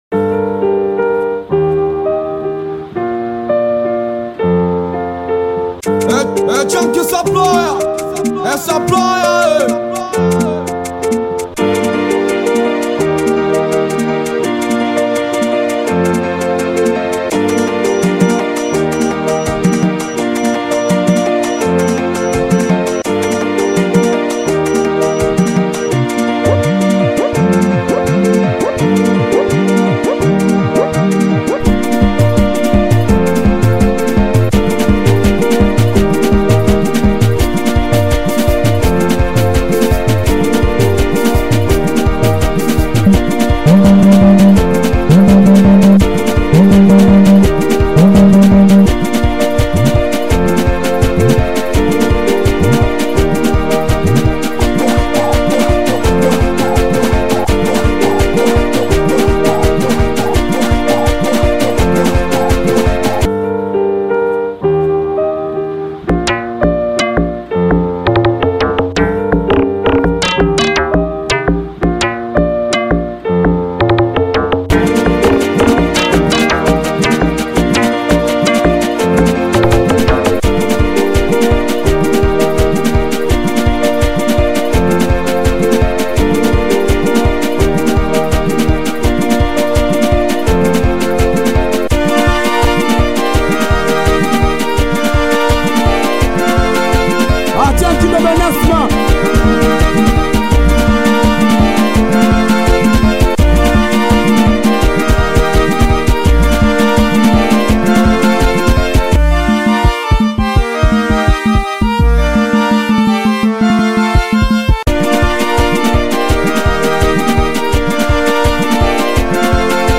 BITI SINGELI • SINGELI BEAT